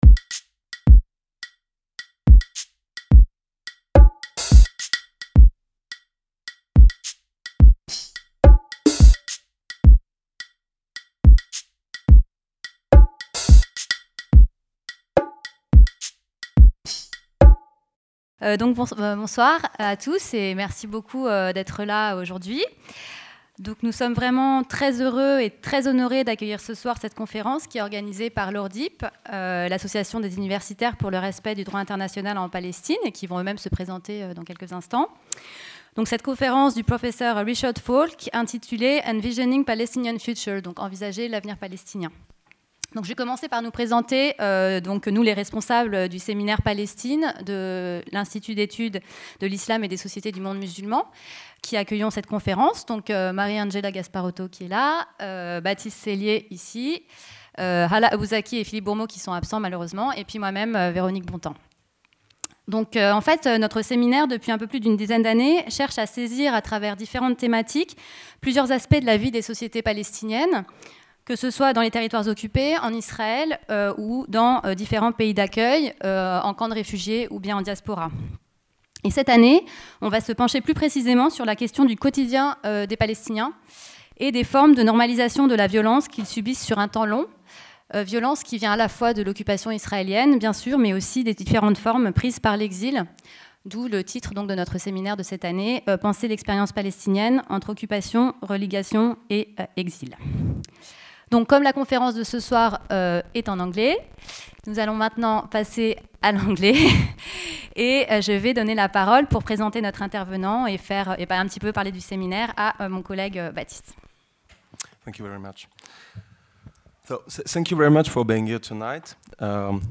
Conférence-débat en anglais de Richard Falk